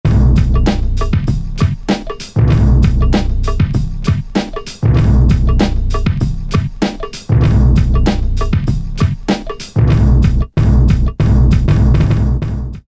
72 70sCopShow Great '70s drum loop plus timpani